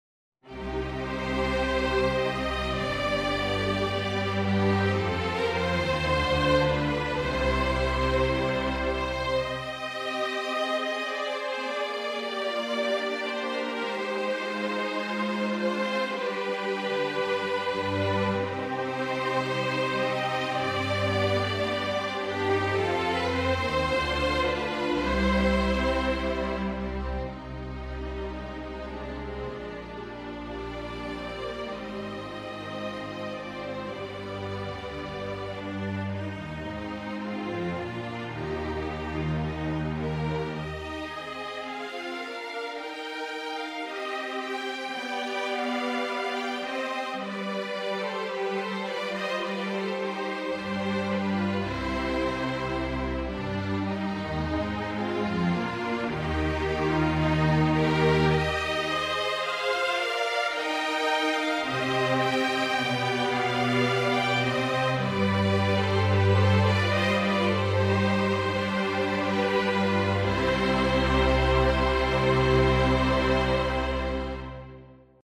String Quartet Wedding Music: Thinking Beyond Canon in D